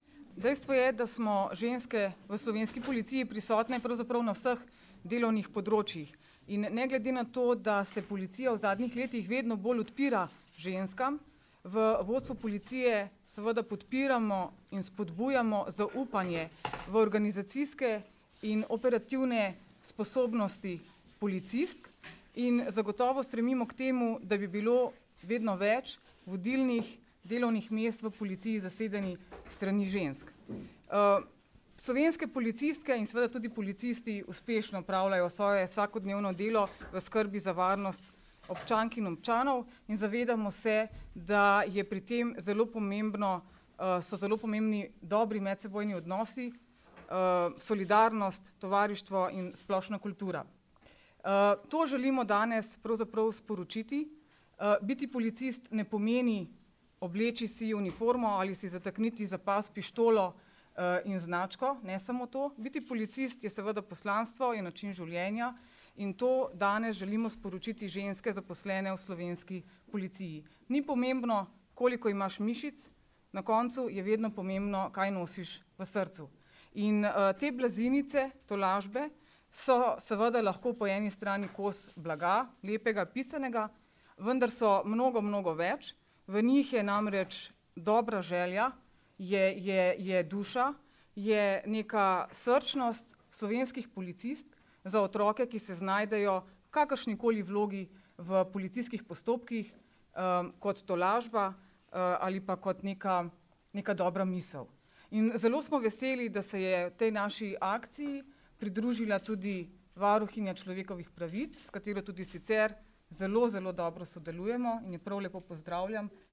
Zvočni posnetek nagovora namestnice generalnega direktorja policije mag. Tatjane Bobnar (mp3)